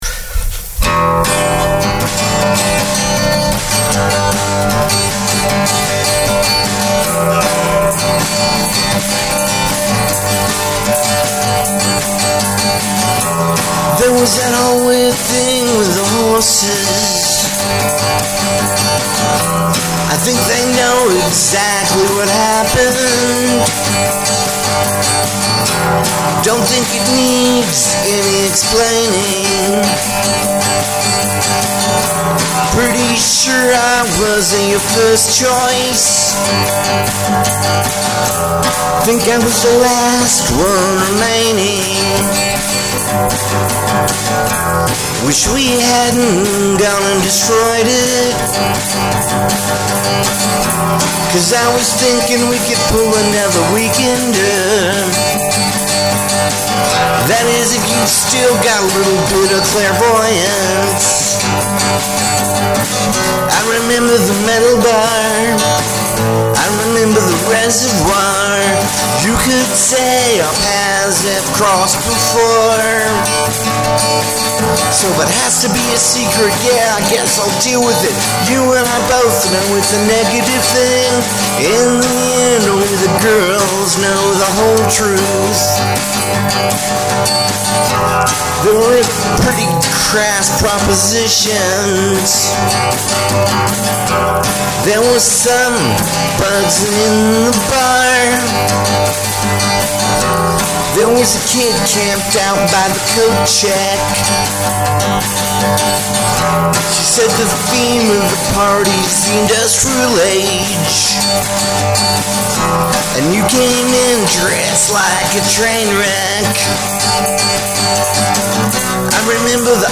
It is a charming acoustic pop song in the vain of NERD ROCK.